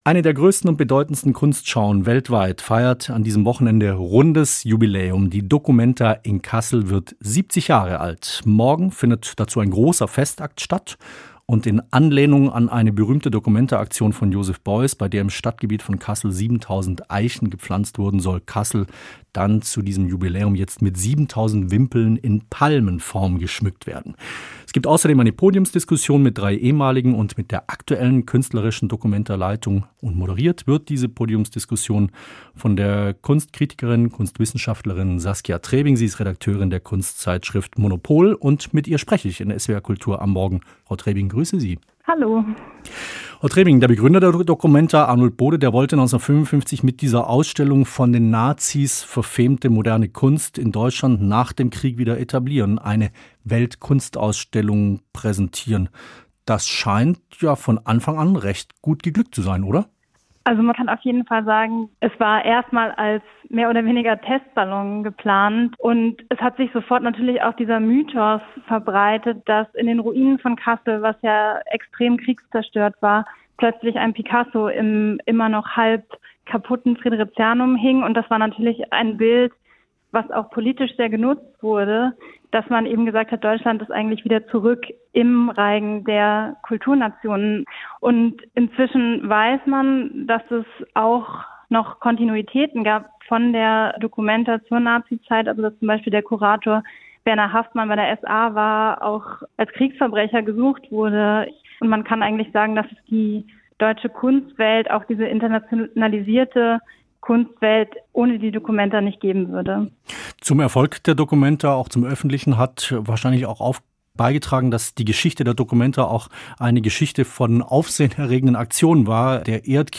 Gespräch über 70 Jahre documenta